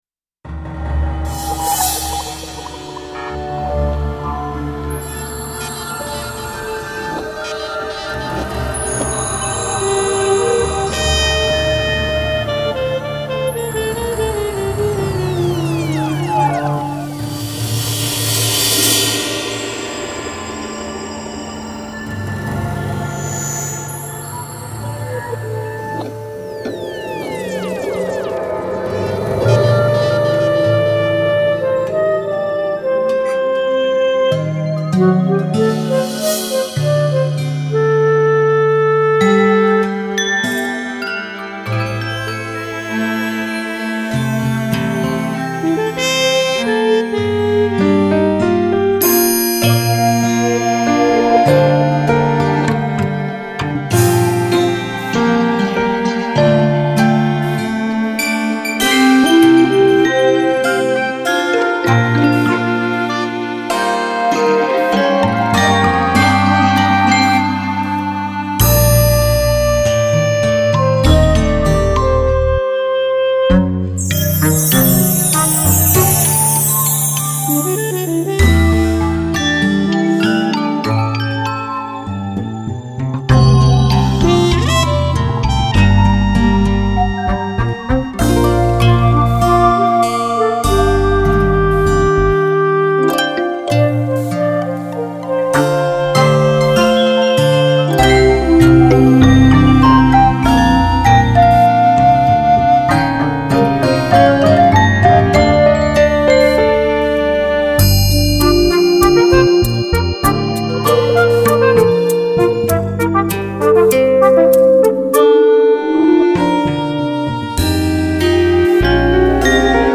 Sea of Tranquility - Jazz, Band, Pop, Rock
On this piece I started with a rather long complex key shifting chord progression.. I created clarinet melody first. Because the chord progression was so complex, I cut and pasted two sections to repeat, to add continuity to the piece.